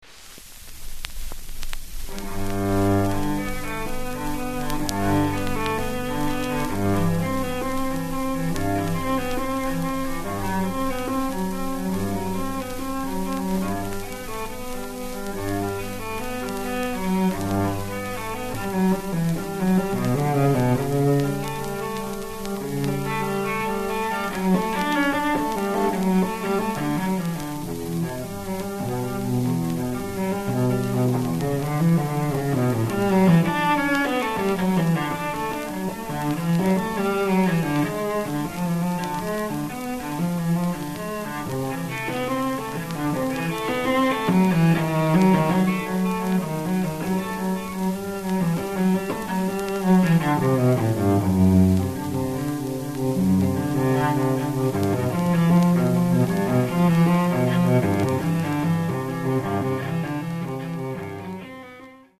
(1938年6月2-4日パリ, アルベール・スタジオ録音)